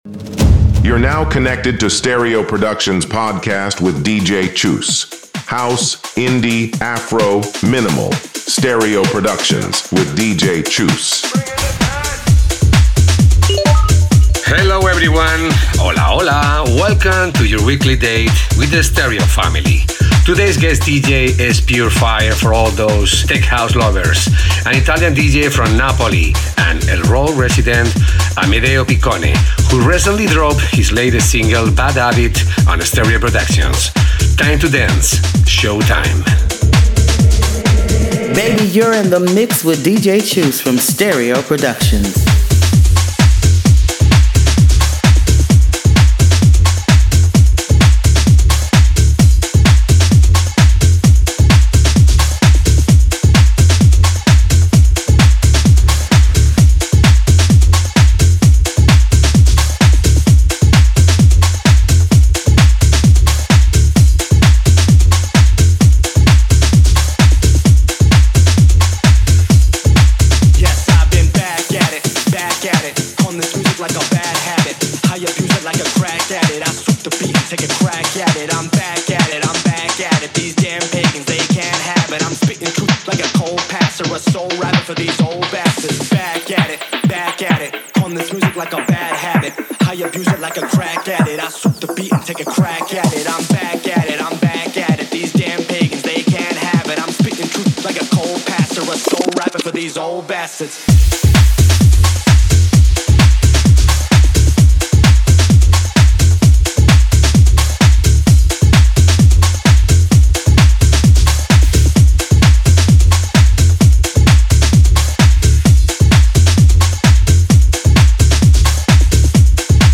tech house rhythms